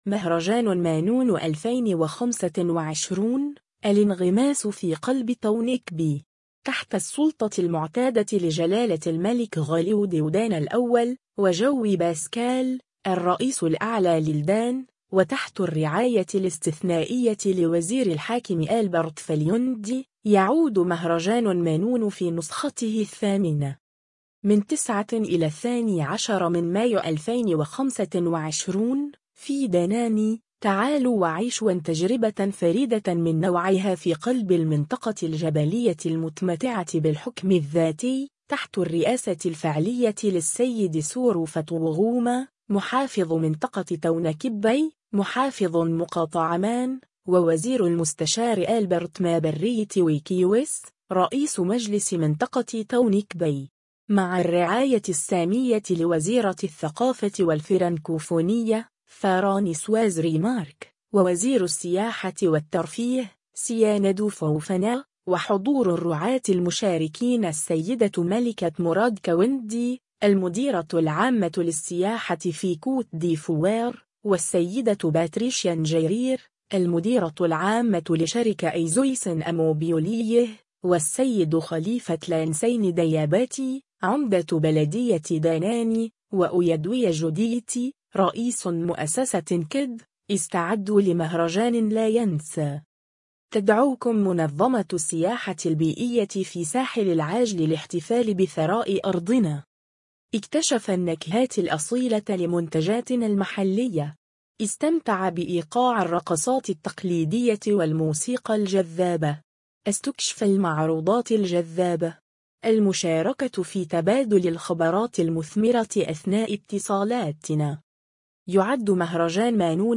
النسخة الصوتية لمقطع الفيديو:
في السابع من مارس 2025، اهتزت قاعة بلدية داناني على إيقاع الإطلاق الرسمي الثاني للنسخة الثامنة من مهرجان مانون.